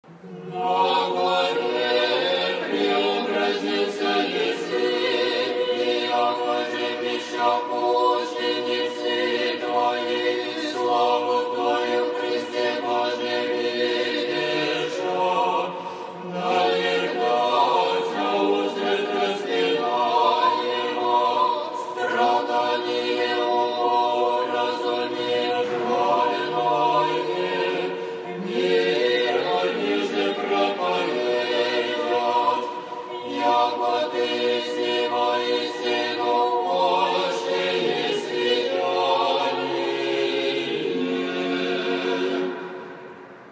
Тропарь в исполнении хора Домового храма св. мц. Татианы.